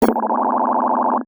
UI_SFX_Pack_61_42.wav